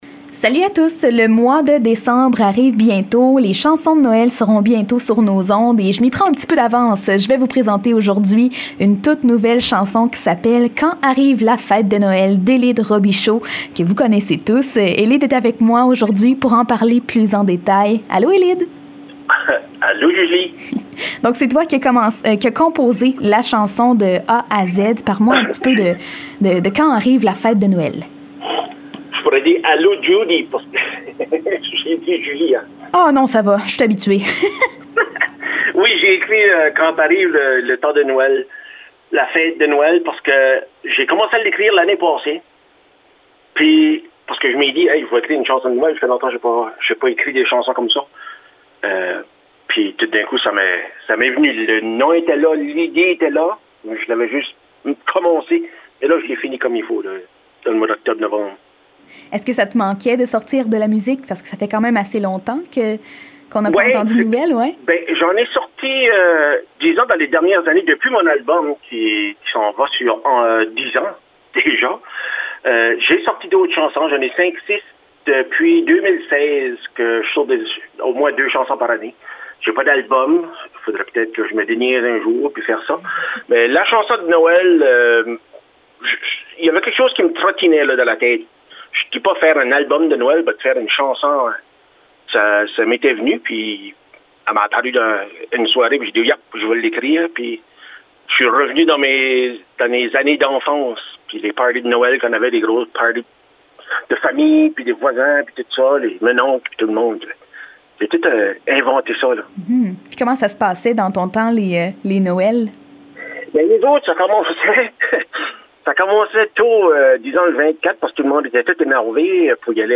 en entrevue au 90.5 FM